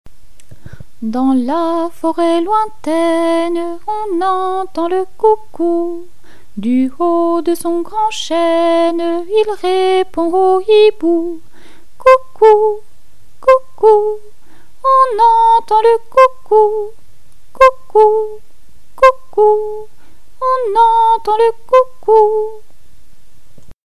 This is a canon for 3 voices.